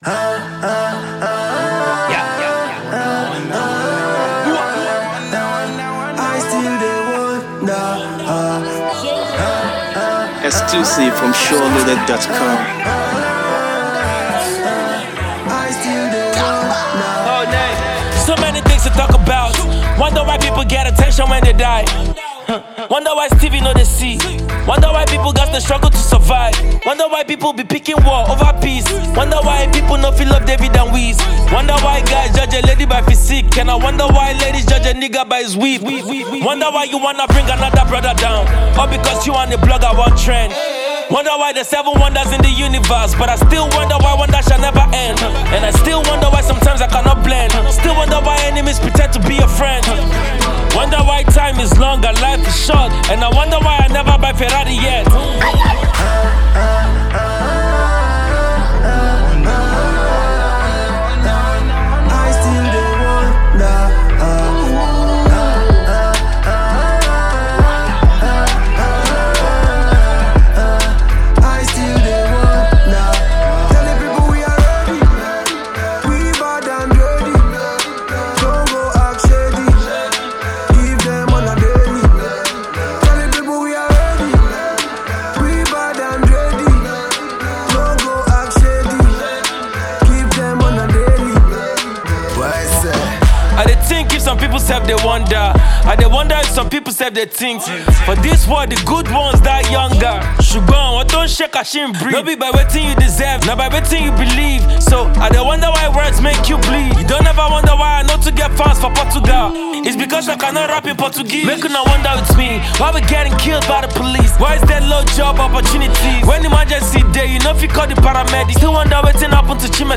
a descriptive song with two verses and a hook
Dancehall